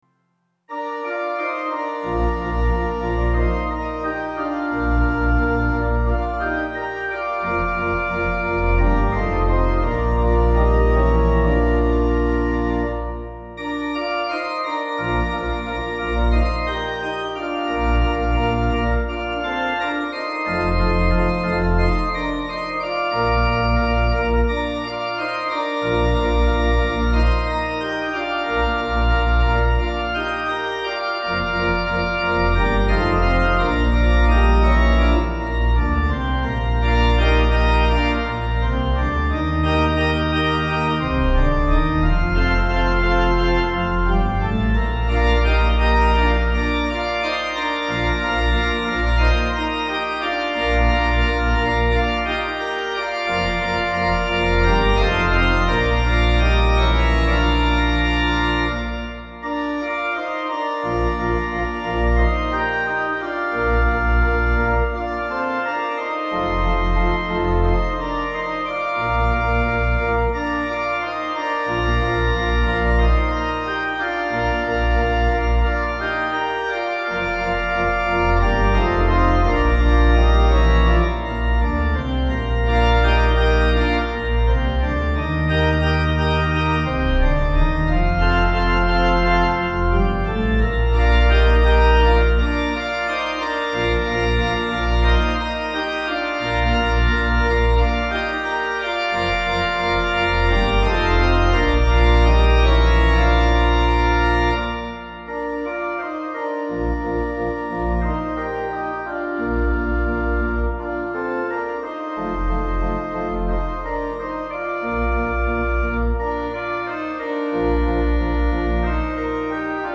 Key: C Major